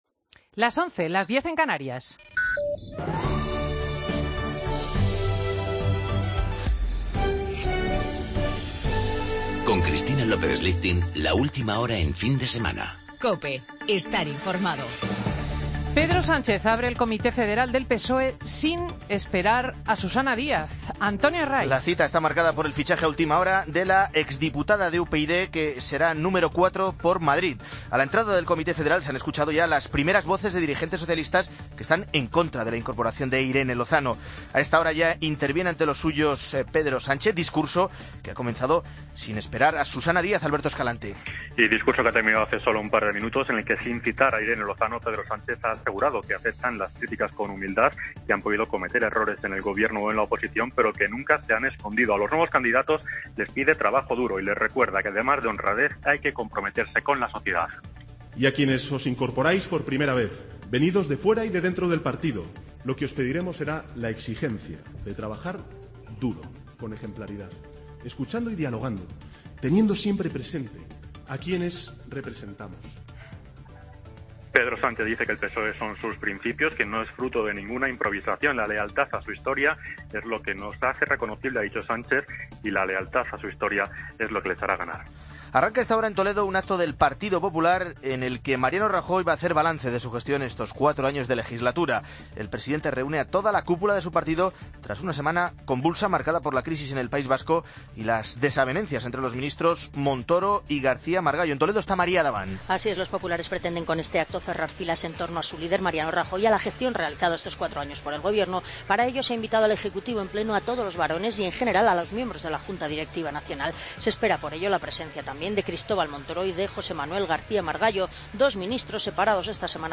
Noticias de las 11.00 horas, sábado 17 de octubre de 2015